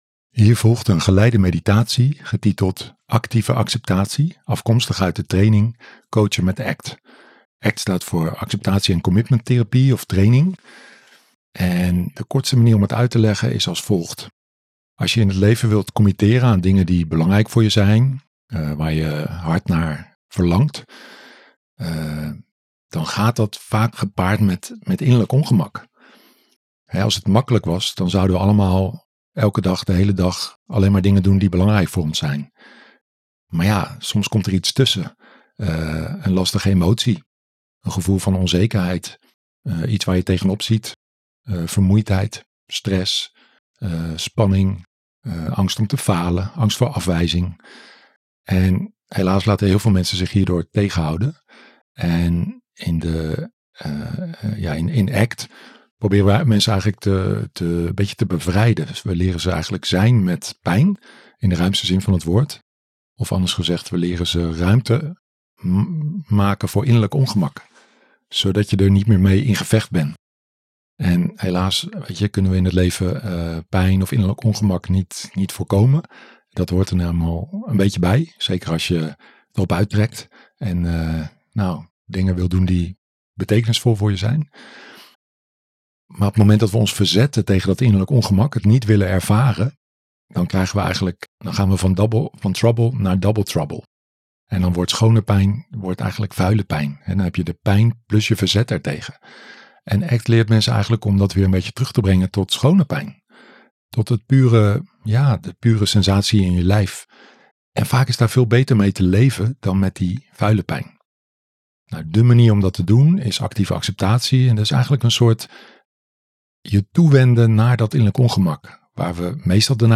#80 Meditatie: Leer ruimte maken voor pijnlijke emoties met 'Actieve Acceptatie'